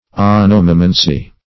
Onomomancy \O*nom"o*man`cy\, n.
onomomancy.mp3